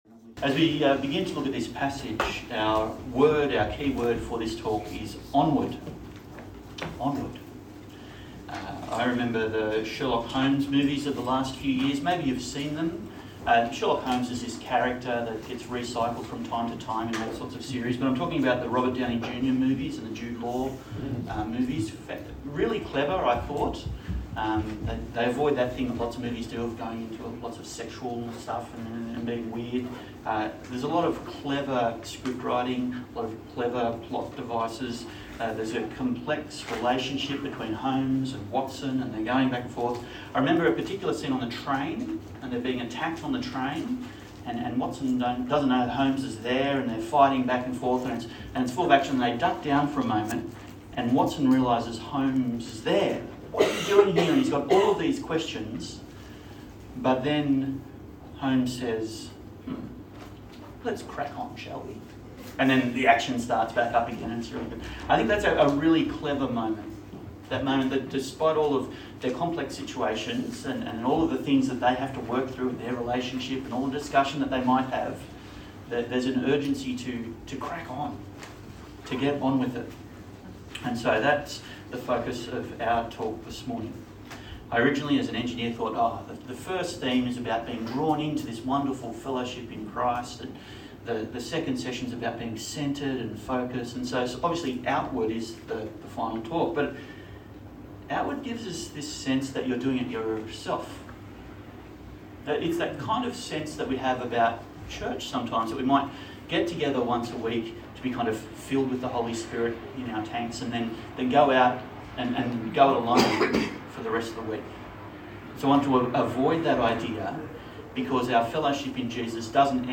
Love Your Church Passage: Hebrews 13 Service Type: Church Camp Talk